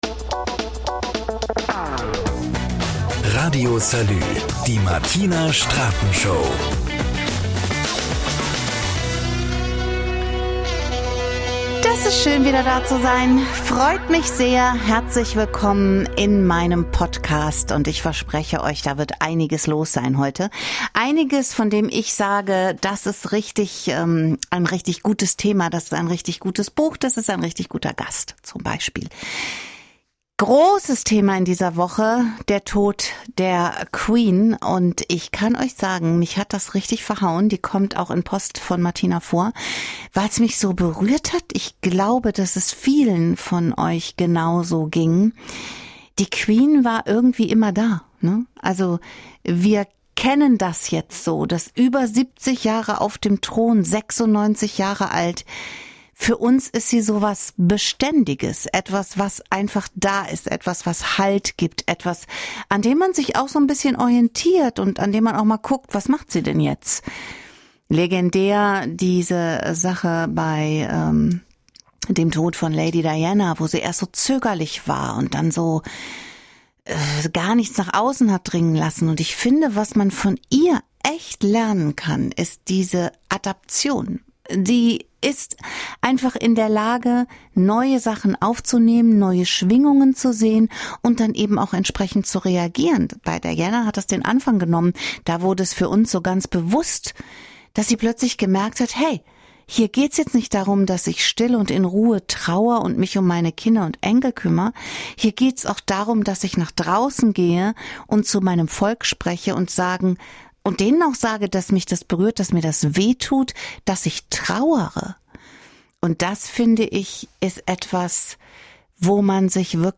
Podcast-News